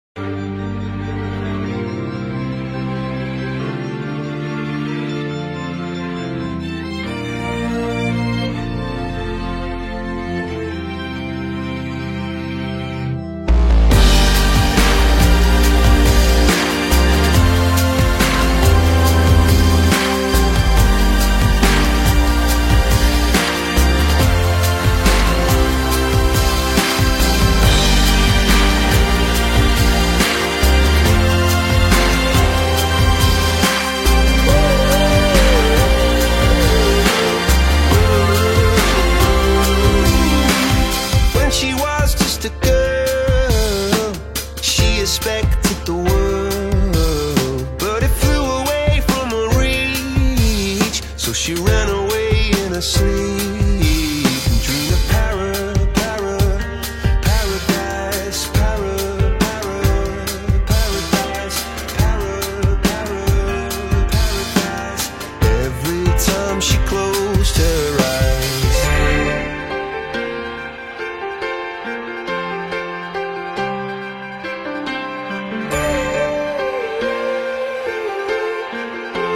pop rock y rock alternativo